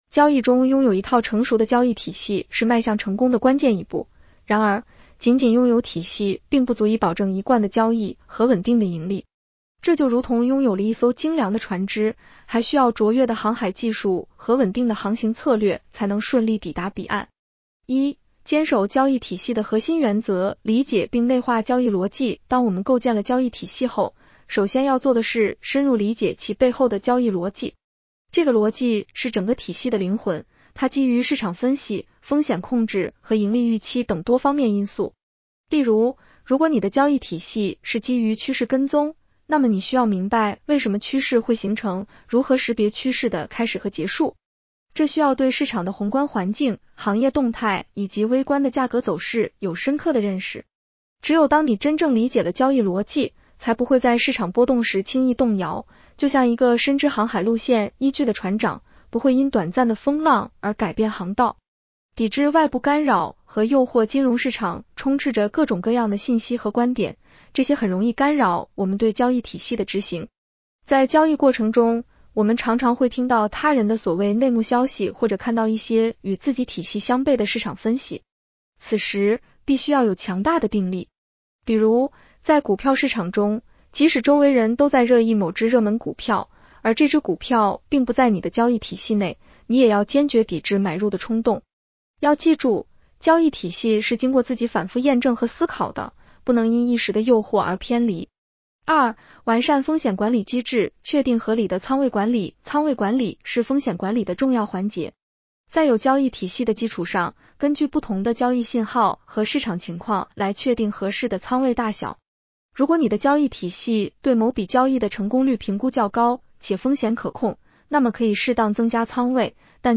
【期货交易夜读音频版】 女声普通话版 下载mp3 交易中拥有一套成熟的交易体系是迈向成功的关键一步，然而，仅仅拥有体系并不足以保证一贯的交易和稳定的盈利。